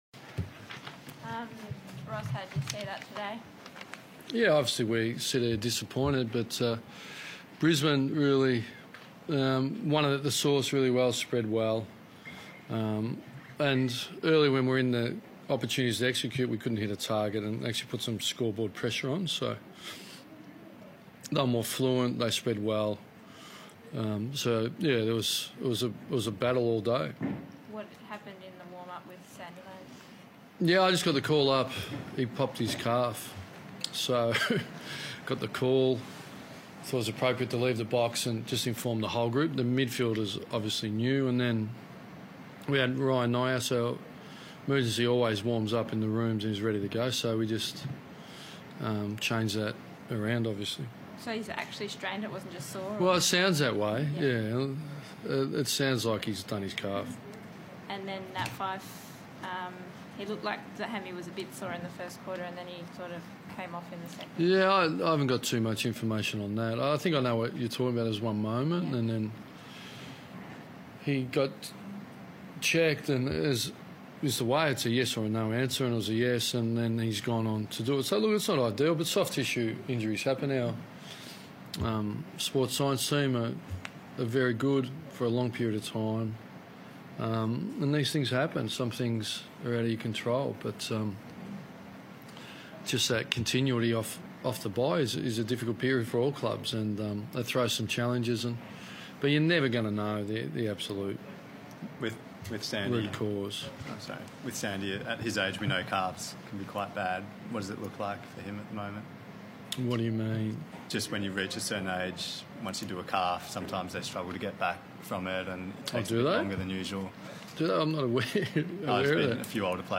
Ross Lyon spoke to the media following the loss against Brisbane.